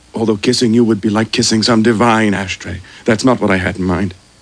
Sound Effects for Windows
ashtray.mp3